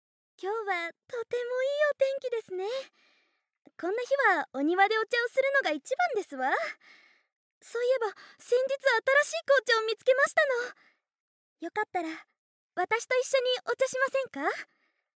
ボイスサンプル
お嬢様